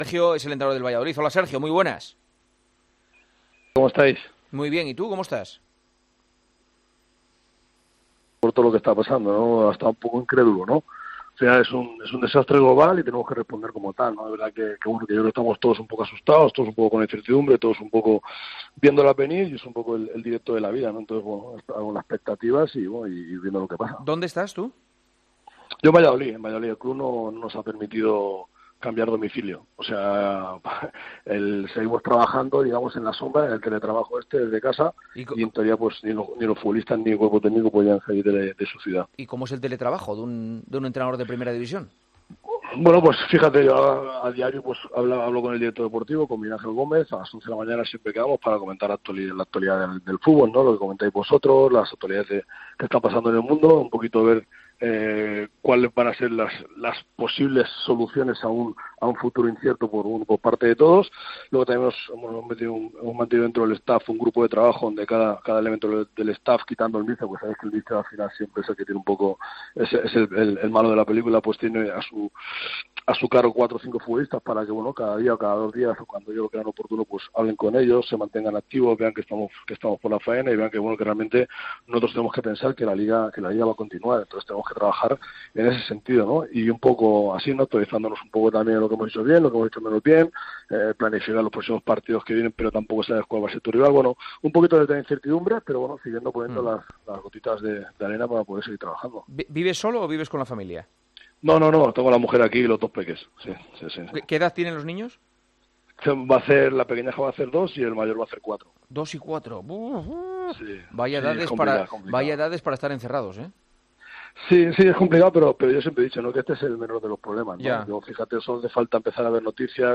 AUDIO: Hablamos con el entrenador del Valladolid sobre la decisión del club de no usar los tests de coronavirus que habían recibido.